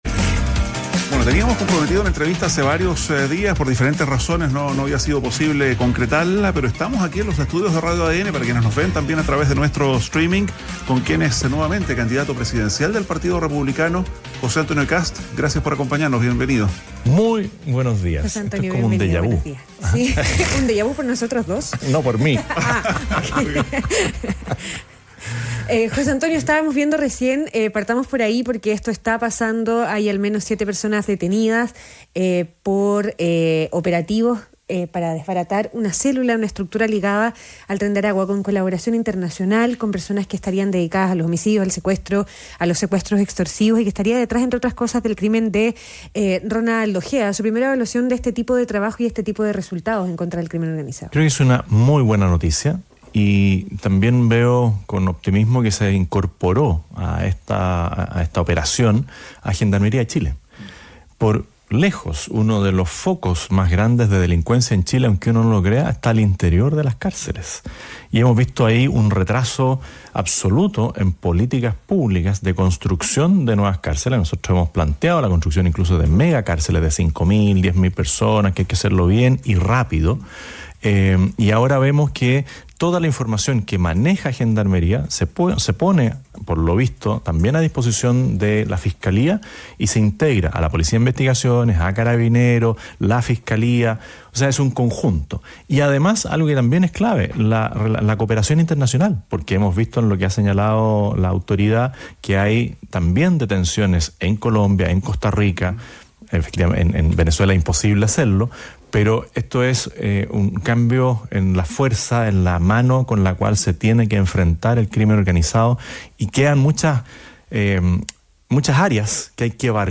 ADN Hoy - Entrevista a José Antonio Kast, candidato presidencial del Partido Republicano